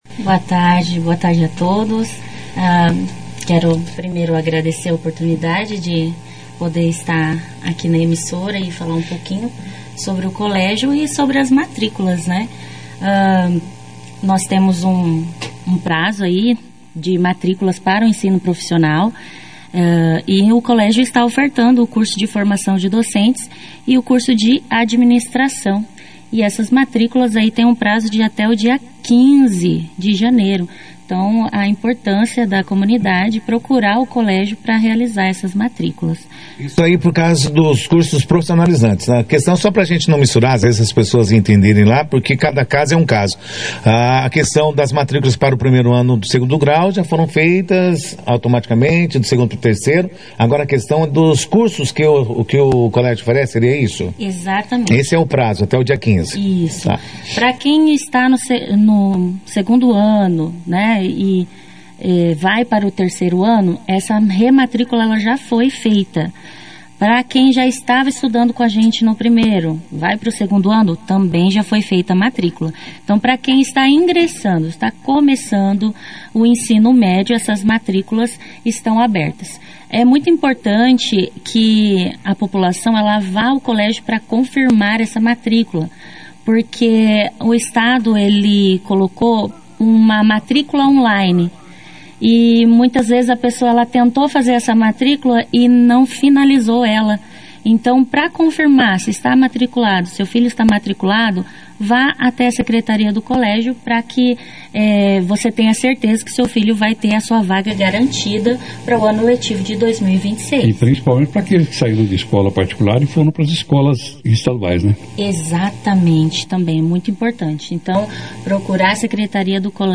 Durante a entrevista, elas falaram sobre o período de matrículas abertas, orientando pais, responsáveis e estudantes sobre os prazos e os procedimentos necessários para garantir a vaga no próximo ano letivo. As representantes da instituição também destacaram a importância dos cursos profissionalizantes, explicando como cada formação contribui para o desenvolvimento pessoal e profissional dos alunos, preparando-os para o mercado de trabalho e para a continuidade dos estudos.